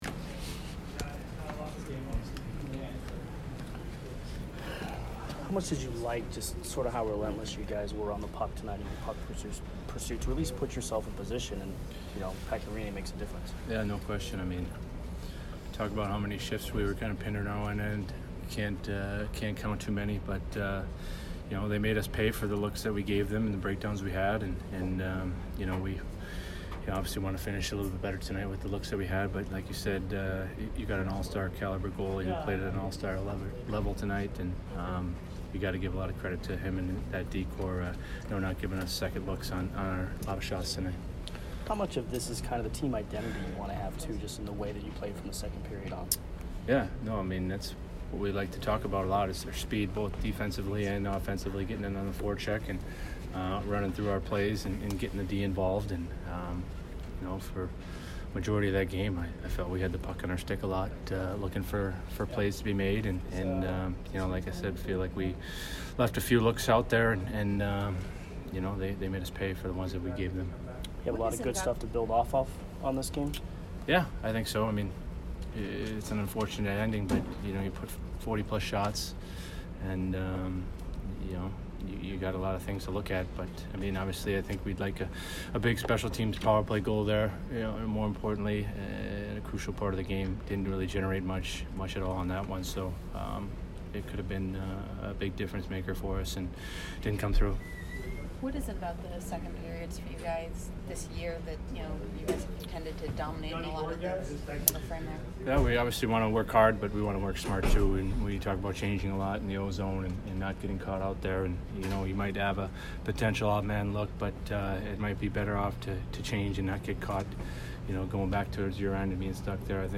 Ryan McDonagh post-game 11/1